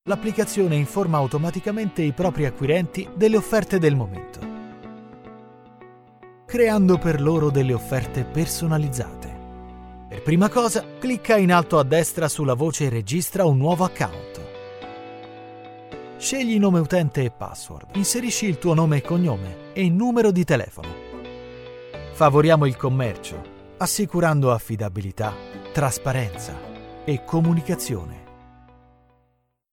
Voice Actor Storyteller and Advertising - Radio Speaker
Sprechprobe: Industrie (Muttersprache):
Some dubbing directors have defined my voice as expressive and versatile.
My voice is bright, expressive, energetic, fresh, sensual, warm, enveloping, suitable for dubbing (voice over - lip sync), reality, documentaries, videogames, audio guides, e-learning, audiobooks, commercials, jingles, podcast and radio. I also work through my Home-studio.